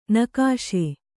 ♪ nakāśe